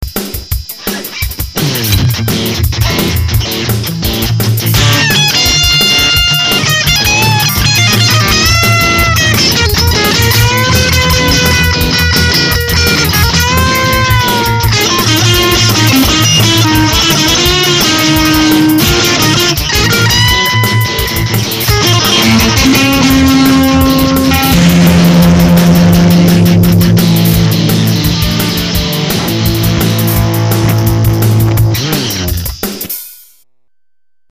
overdrive.mp3